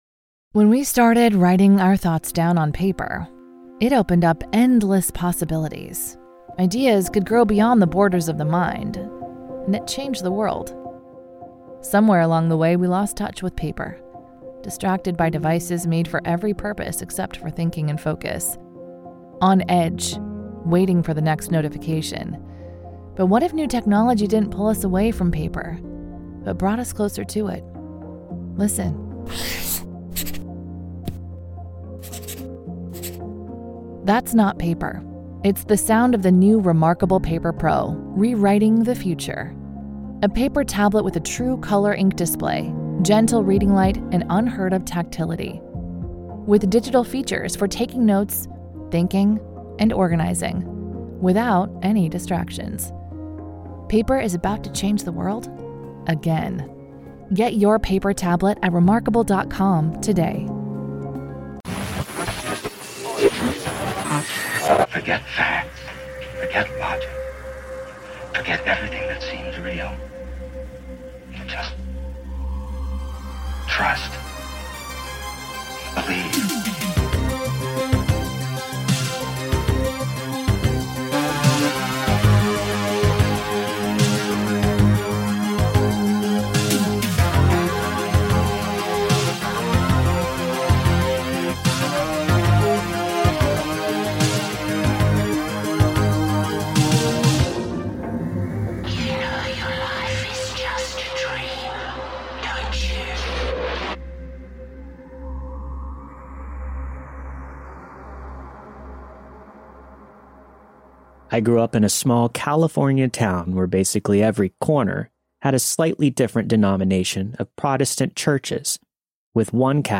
All of the stories you've heard this week were narrated and produced with the permission of their respective authors.